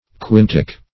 Search Result for " quintic" : The Collaborative International Dictionary of English v.0.48: Quintic \Quin"tic\, a. [L. quintus fifth, fr. quinque five.]
quintic.mp3